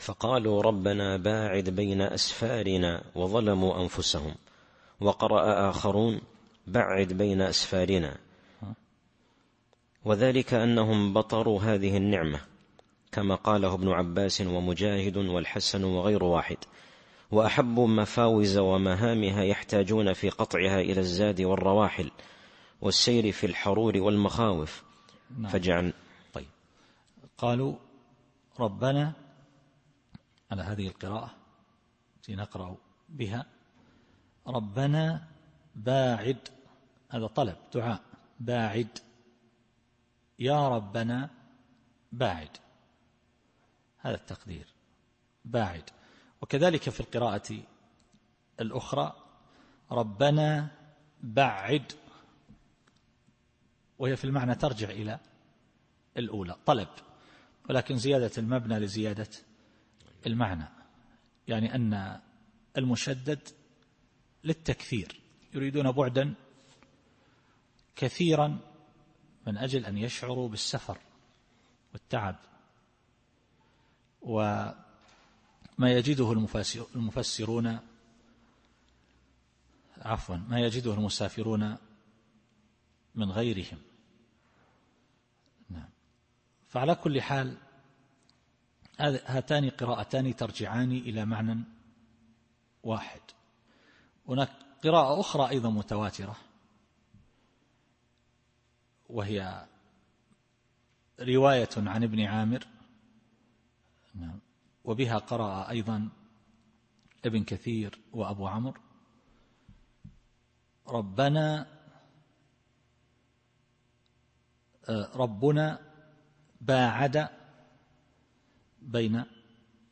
التفسير الصوتي [سبأ / 19]